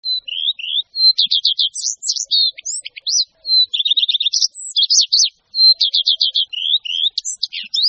En cliquant ici vous entendrez le chant du Rossignol Philomèle.
Le Rossignol Philomèle